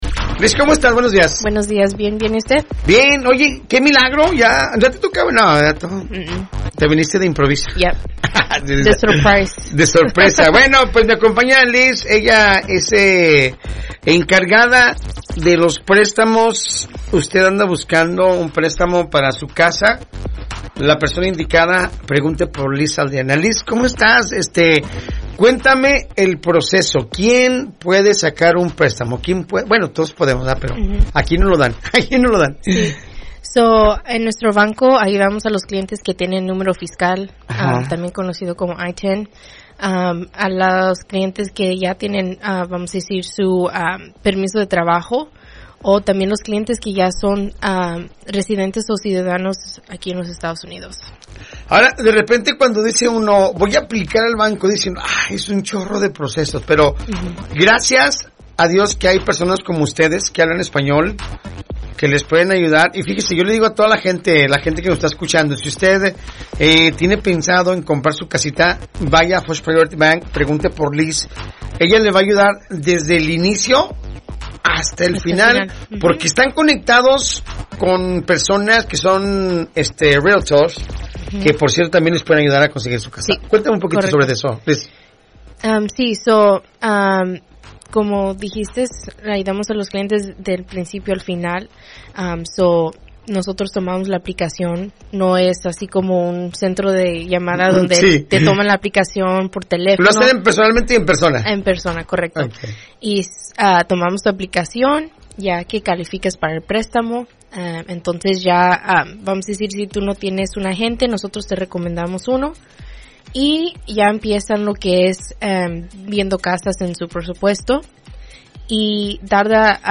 Entrevista-FirstPryorityBank-02Septiembre25.mp3